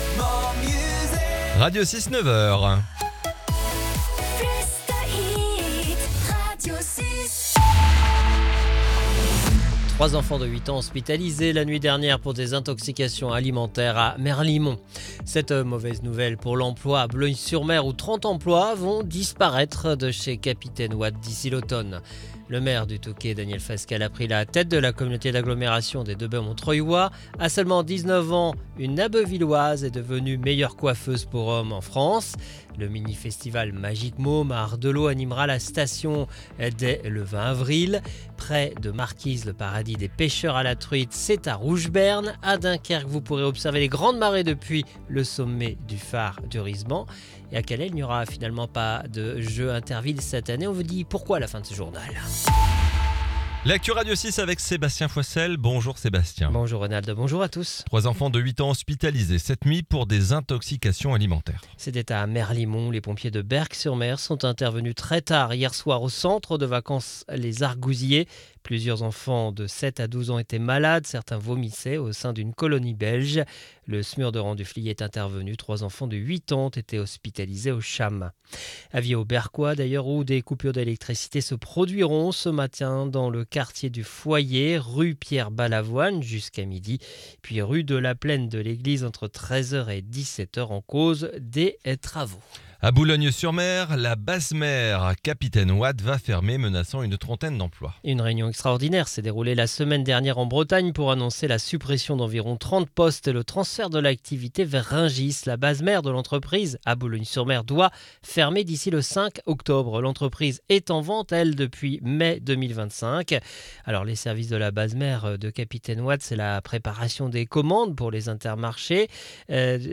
Le journal du jeudi 16 avril 2026